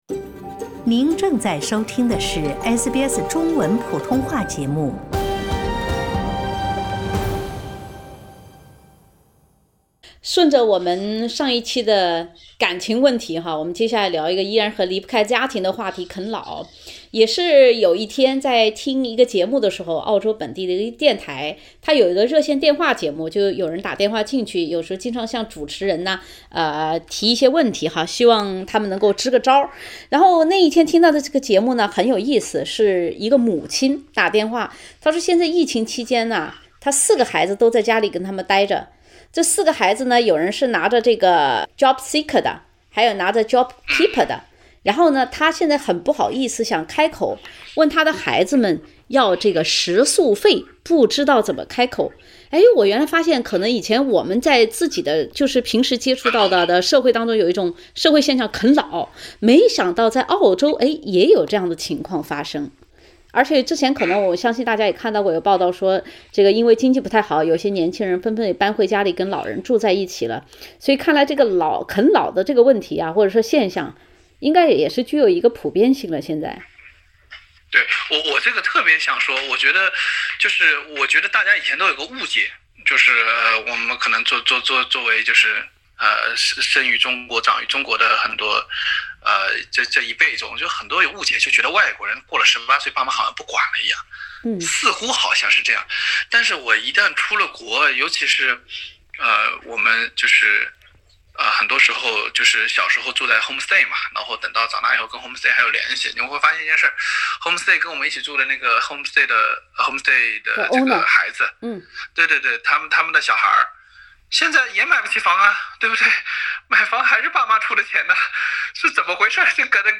Source: E+ SBS 普通话电台 View Podcast Series Follow and Subscribe Apple Podcasts YouTube Spotify Download (41.39MB) Download the SBS Audio app Available on iOS and Android 疫情期间一些拿着寻工津贴、留工津贴的澳洲年轻人出于生计重新回到了父母的“怀抱”。